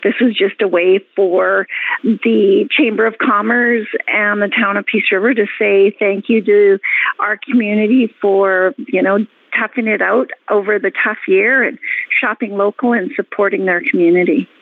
Je me suis entretenue avec Johanna Downing, conseillère municipale de la ville de Peace River: